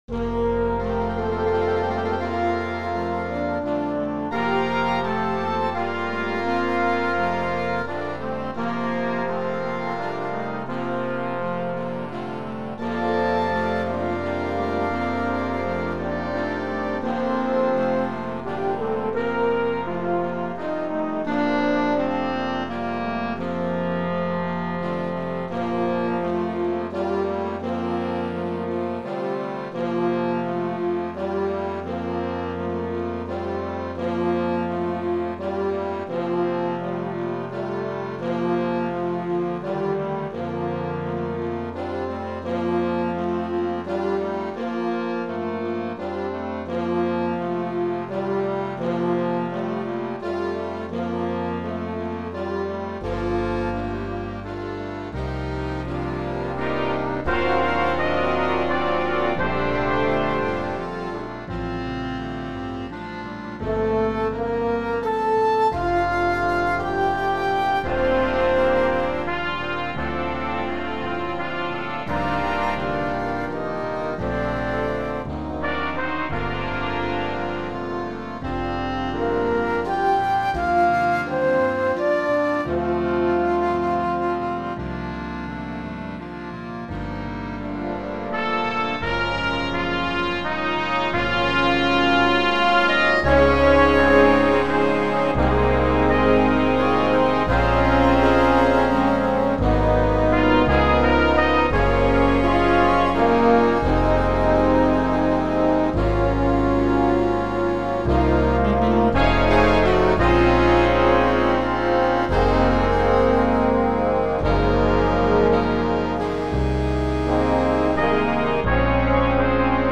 Fl�jt 1 Fl�jt 2 Altfl�jt Klarinett 1 Klarinett 2 Klarinett 3 Basklarinett Horn 1 Horn 2